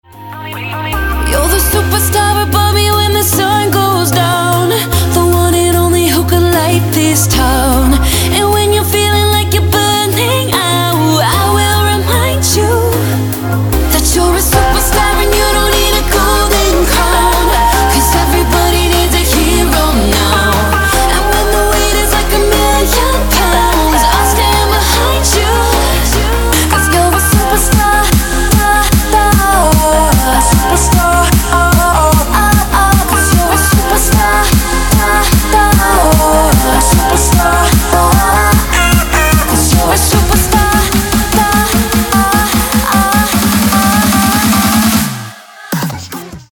• Качество: 160, Stereo
женский вокал
dance
Electronic
club
vocal